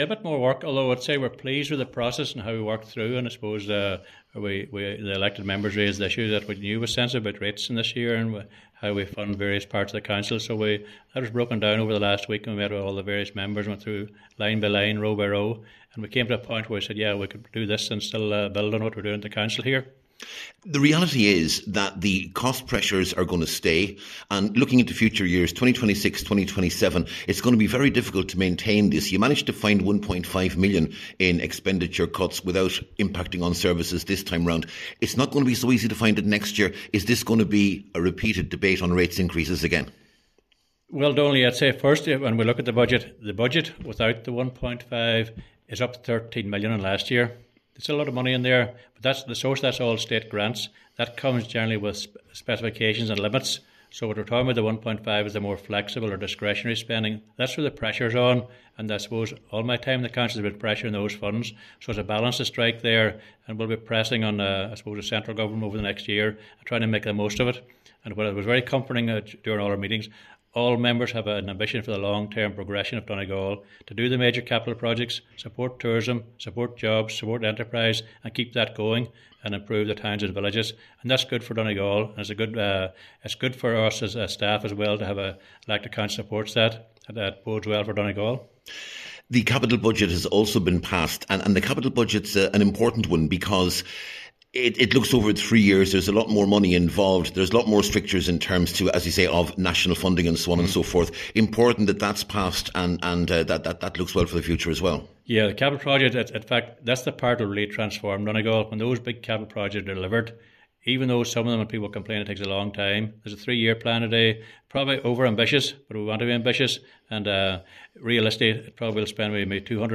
The Council’s Chief Executive John McLaughlin explains where they made the savings: